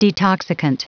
Prononciation du mot detoxicant en anglais (fichier audio)
Prononciation du mot : detoxicant